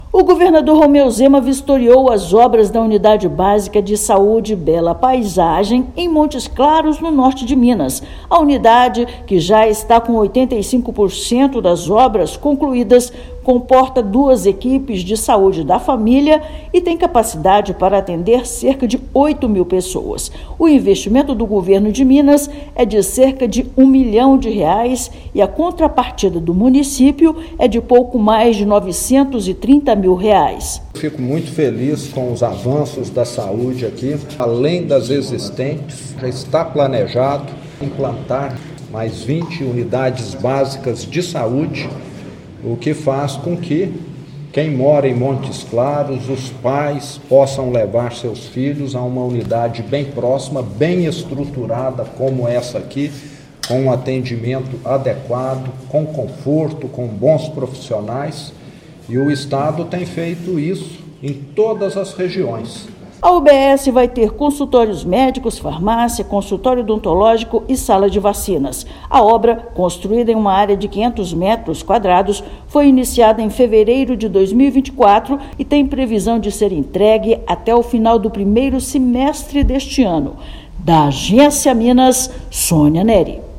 UBS Bela Paisagem tem mais de 85% das obras concluídas e deve atender cerca de 8 mil pessoas após a conclusão, prevista para este ano. Ouça matéria de rádio.